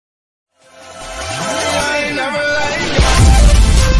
Free Foley sound effect: Toilet Flush.
Toilet Flush
Toilet Flush is a free foley sound effect available for download in MP3 format.
385_toilet_flush.mp3